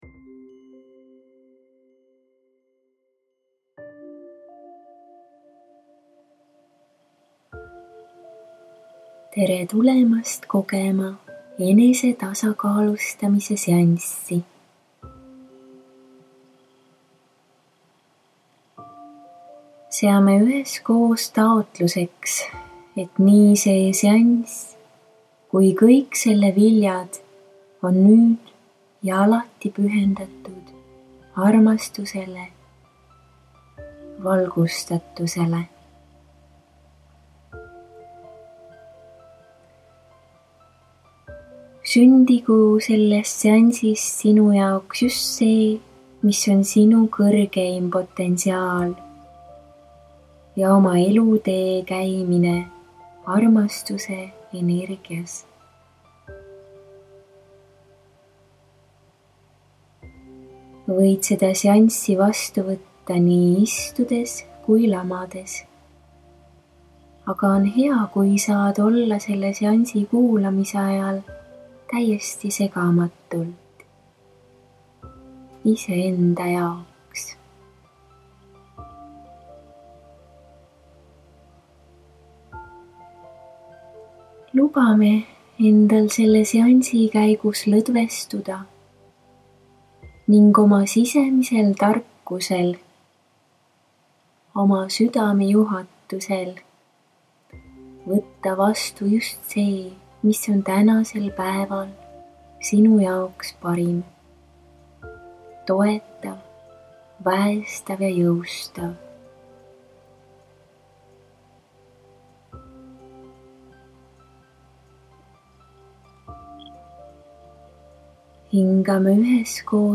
SIIT LEHELT SAAD KUULATA MEDITATSIOONI